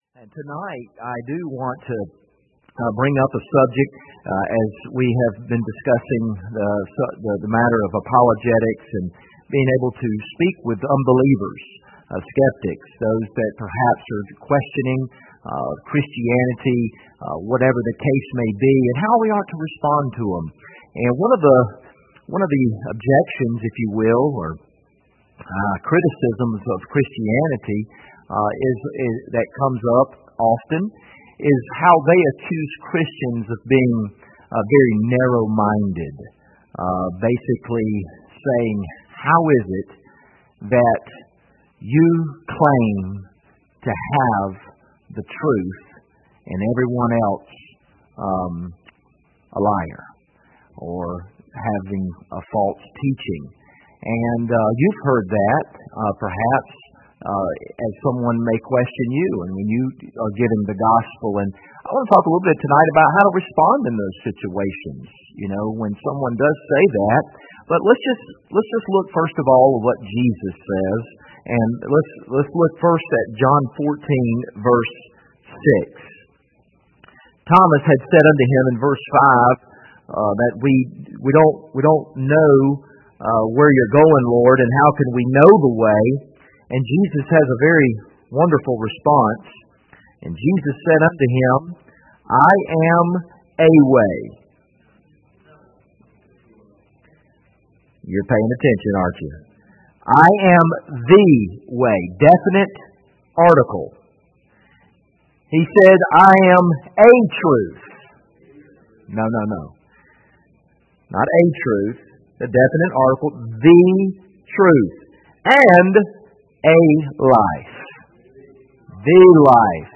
John 14:6 Service Type: Wednesday Evening View the video on Facebook « That They Might Be Saved He Must Increase